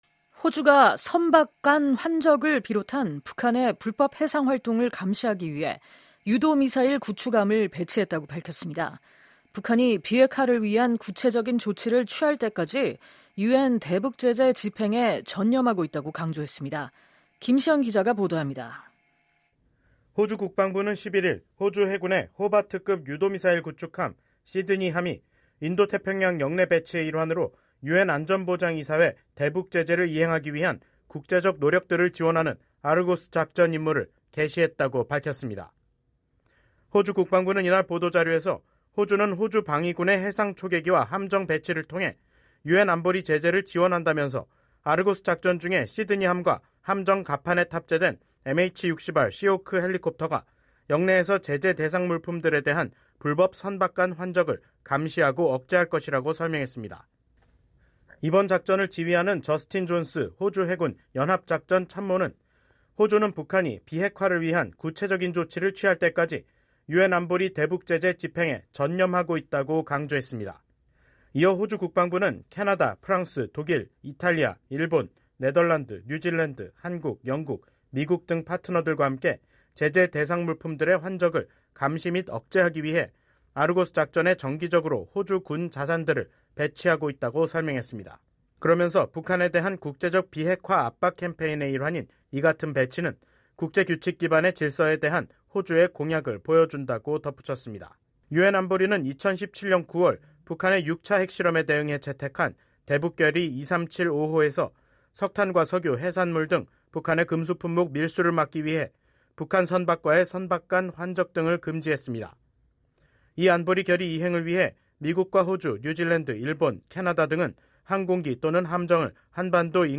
보도입니다.